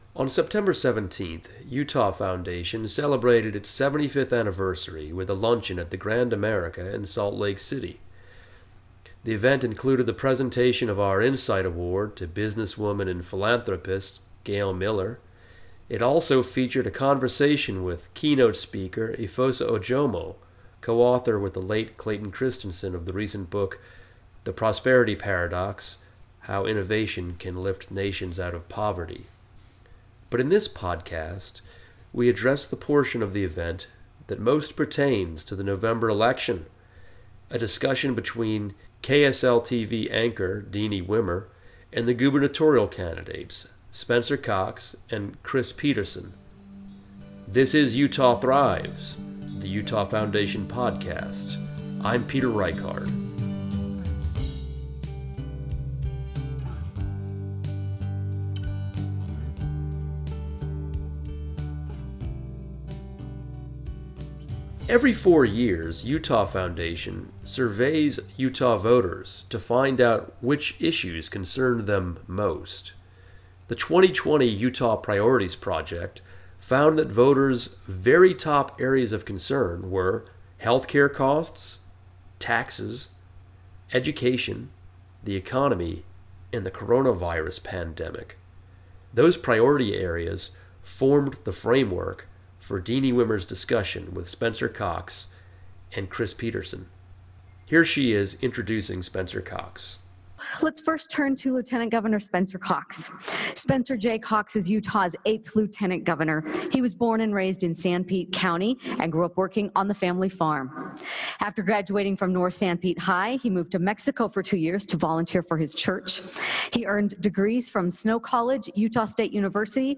Utah Thrives Podcast | A Discussion with Utah’s Next Governor